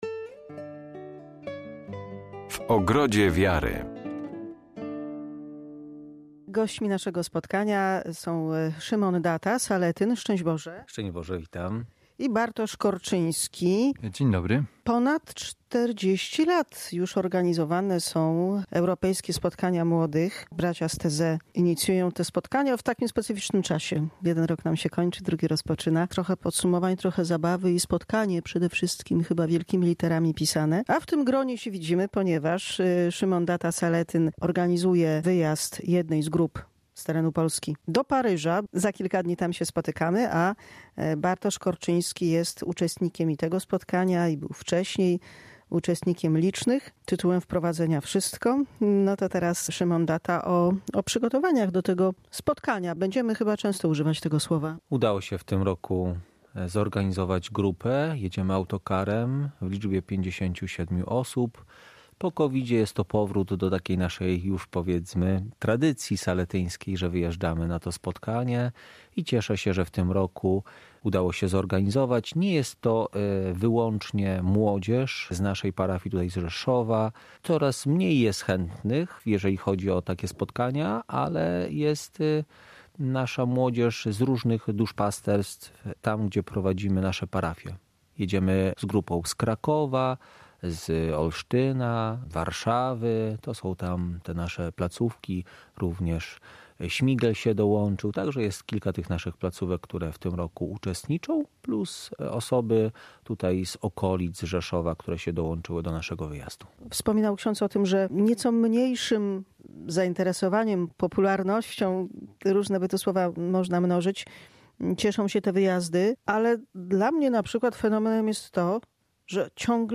Europejskie Spotkanie Młodych w Paryżu. Rozmowa z uczestnikami